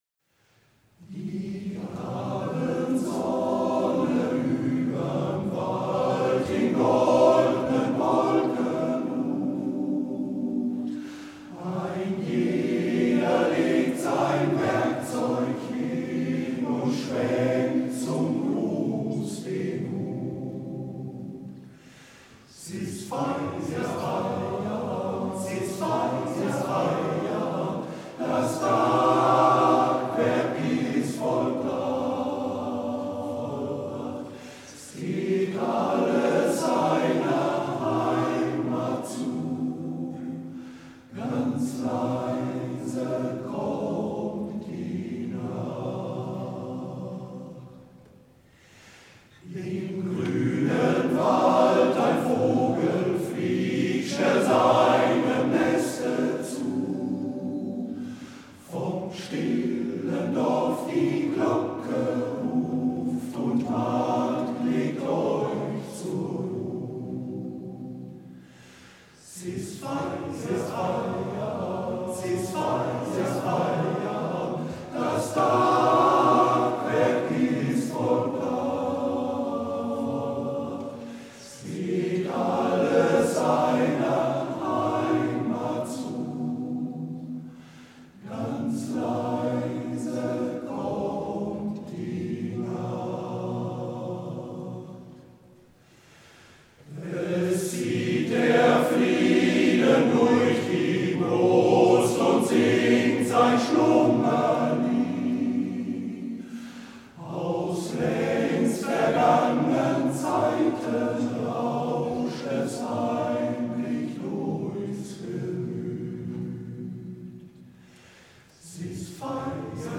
Zur Karte der Stelenstandorte "Feierabend" gesungen vom MGV "Cäcila" Volkringhausen e.V. Um das Lied zu hören, bitte auf unser Bild klicken.